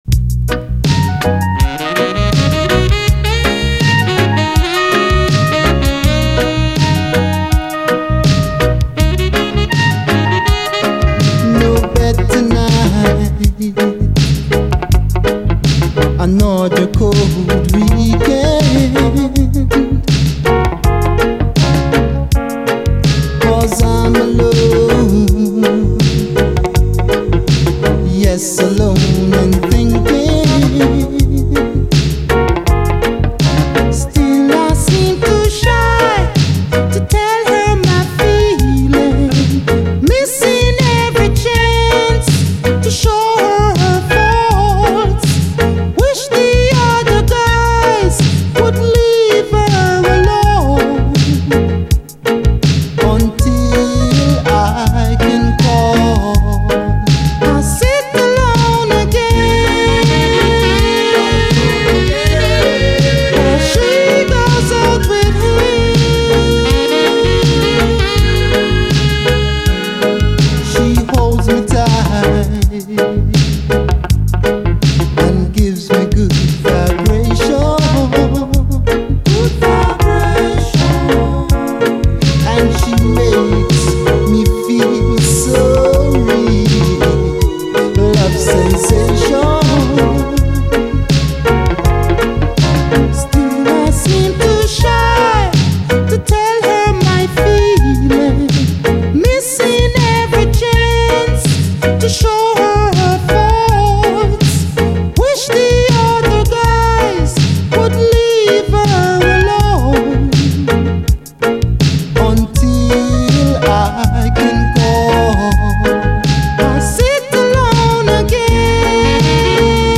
隠れたキラーUKラヴァーズ！
トロトロの甘々メロディーと突き抜けるようなサビがラヴァーズに完全にハマってるキラー・チューンです！